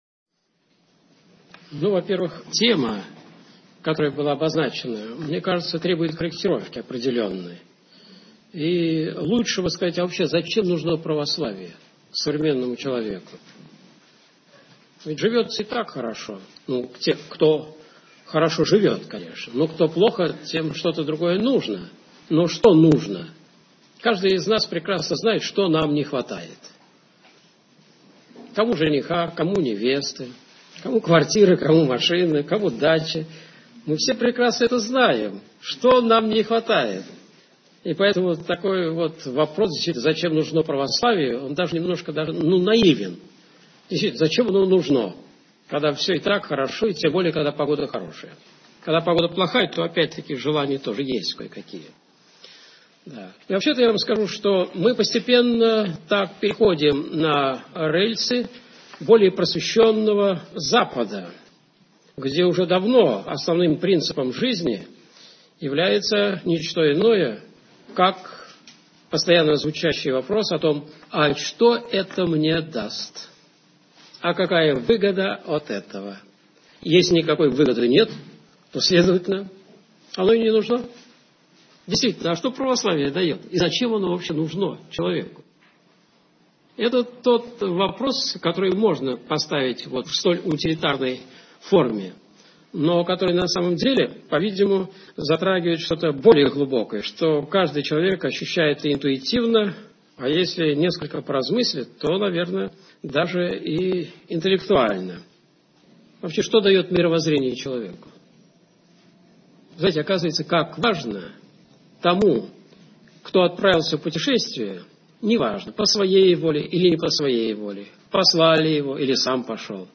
Что дает человеку Православие? Лекция профессора Осипова